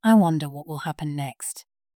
Kiejtés: /ˈwʌn.dər/